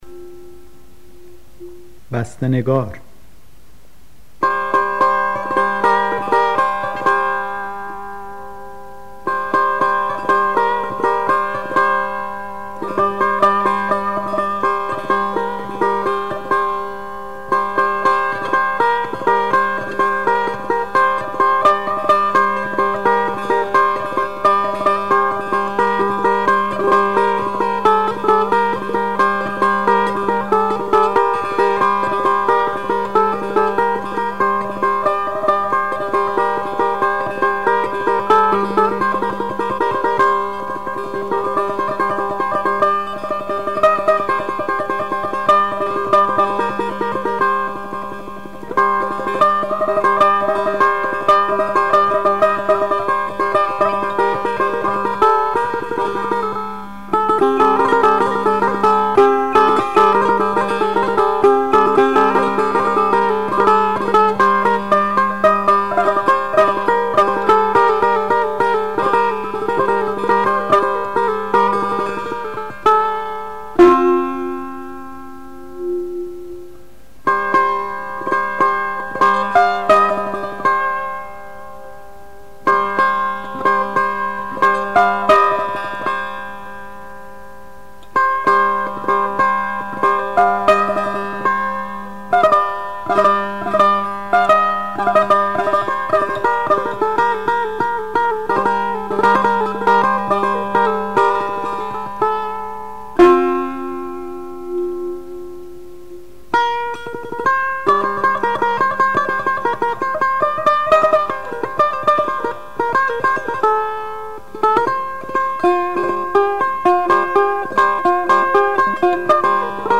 آواز افشاری ردیف میرزا عبدالله سه تار
استاد طلایی در اجرای خود با ساز سه تار، به خوبی توانسته است همانندی‌های این آواز را با دستگاه‌های نوا و ماهور به نمایش گذارد.